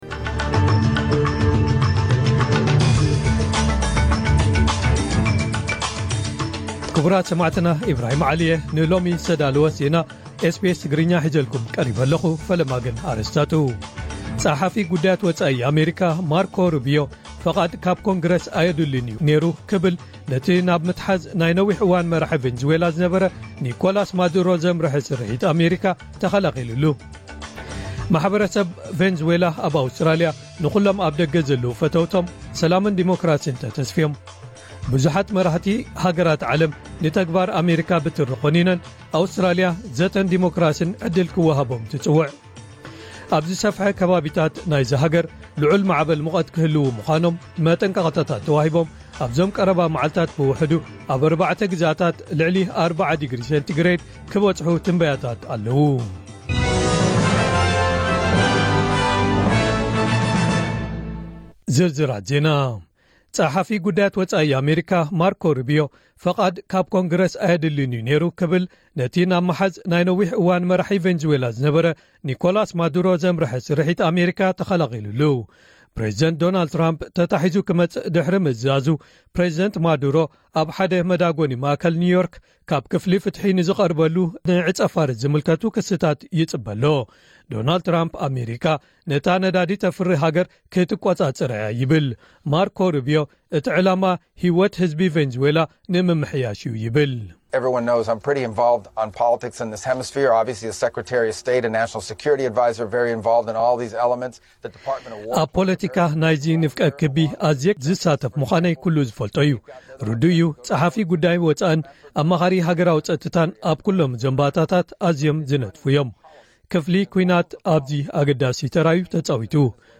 ዕለታዊ ዜና ኤስቢኤስ ትግርኛ (5 ጥሪ 2026)